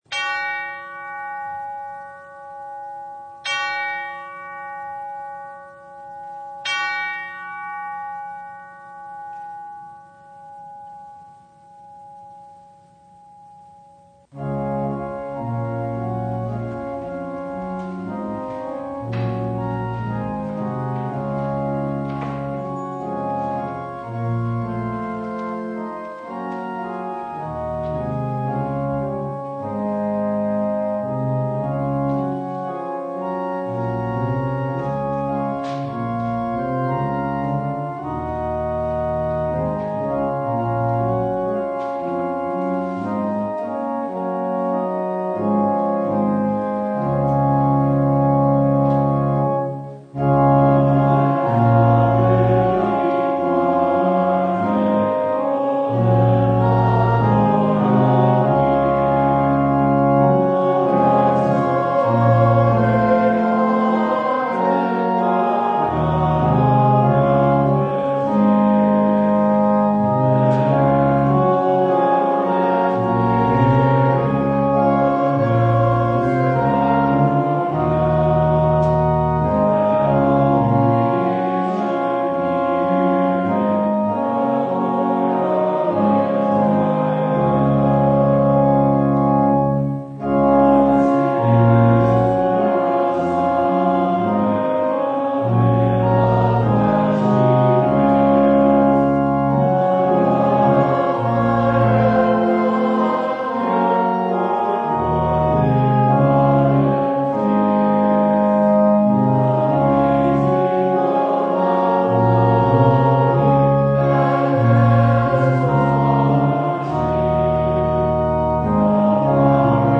Service Type: Christ the King
Download Files Notes Bulletin Topics: Full Service « The Parable of the Talents Our Judgment or Christ’s?